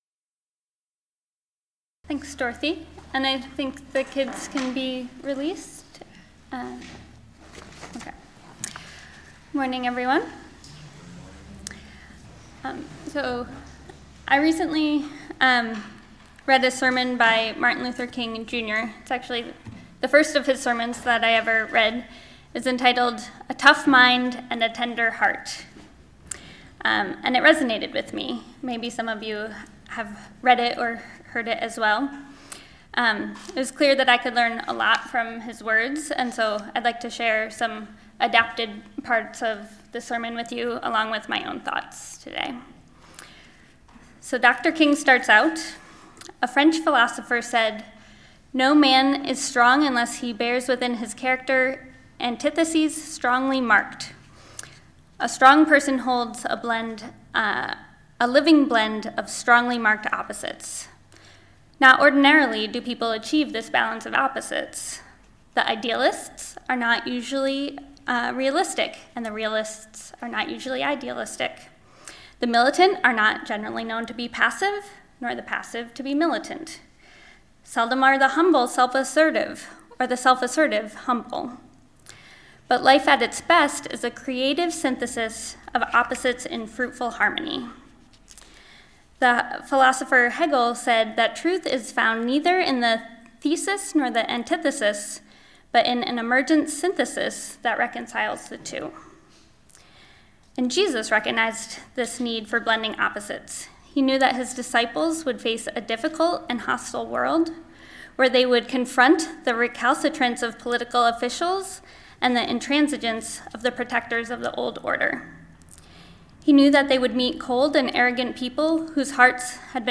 Listen to the most recent message (“Be Wise… Be Innocent”) from Sunday worship at Berkeley Friends Church.